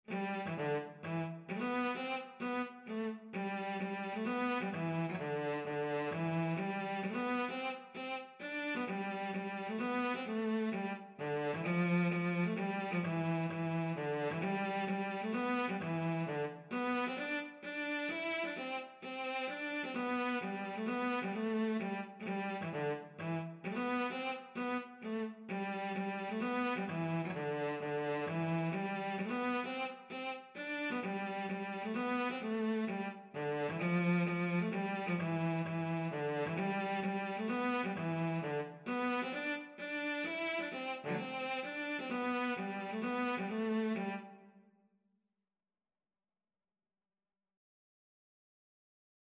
Cello version
G major (Sounding Pitch) (View more G major Music for Cello )
3/4 (View more 3/4 Music)
D4-E5
Cello  (View more Easy Cello Music)
Traditional (View more Traditional Cello Music)